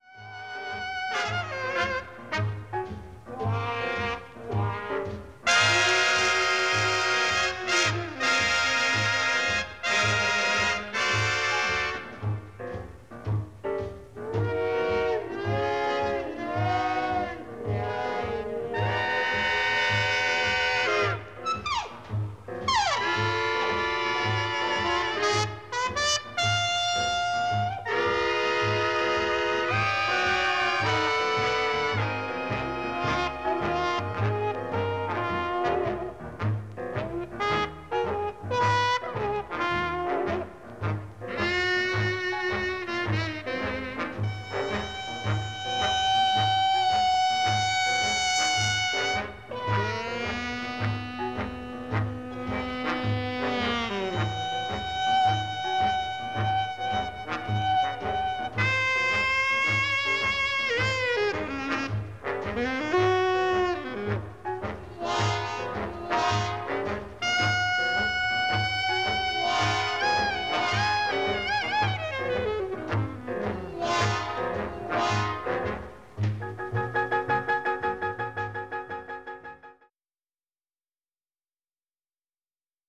baritone sax